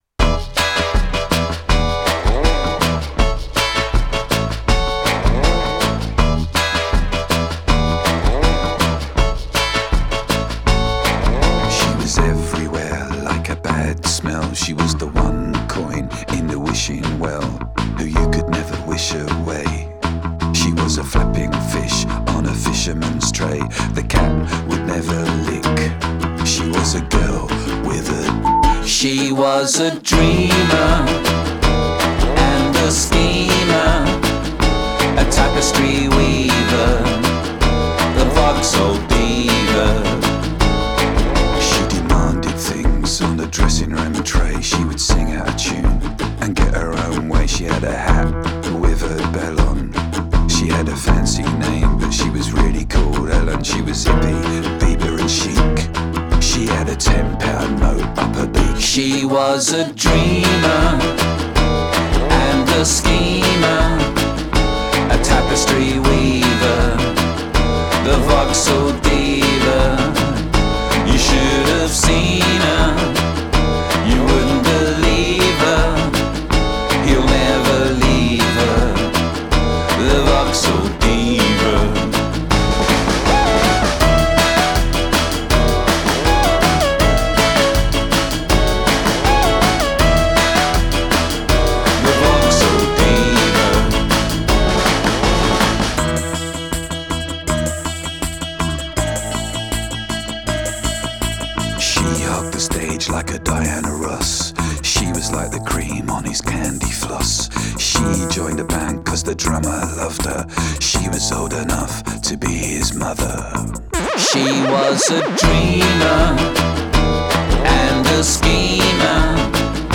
goes all music hall